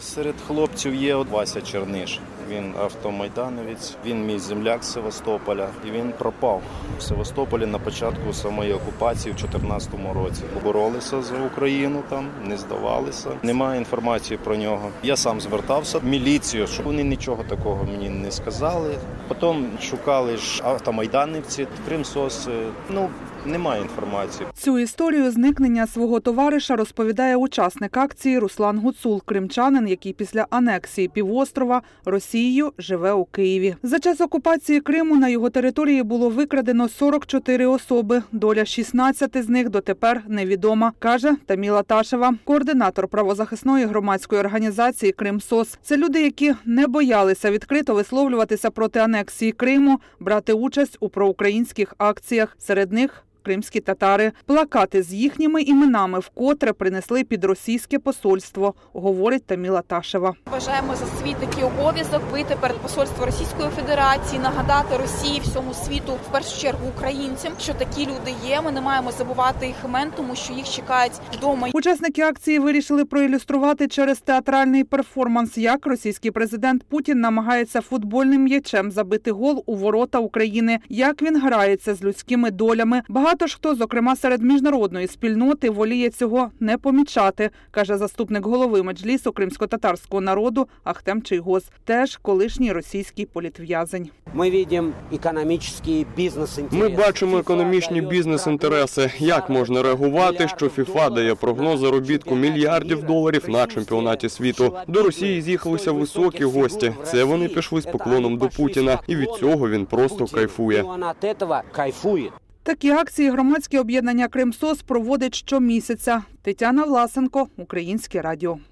Акція з такою назвою відбулась сьогодні  у Києві біля російського посольства.